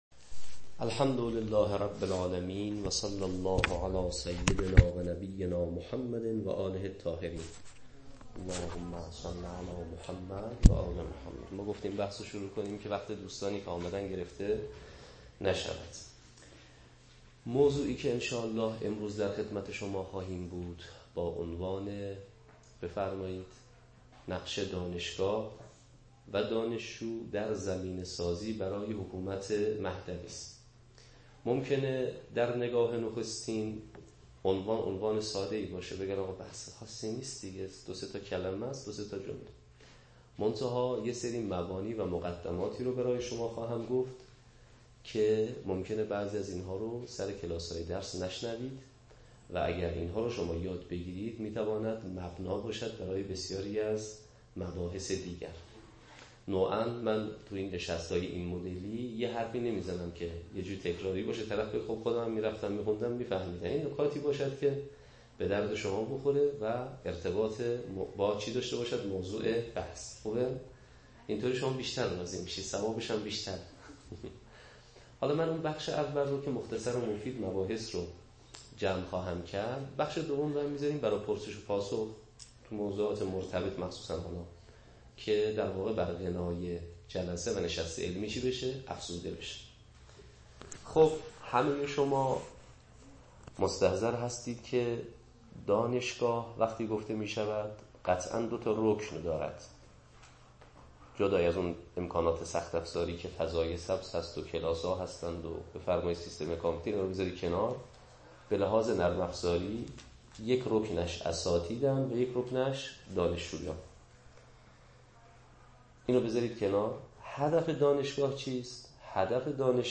نقش حوزه و دانشگاه در زمینه سازی ظهور دولت مهدوی به همراه پرسش و پاسخ دانشجویی.mp3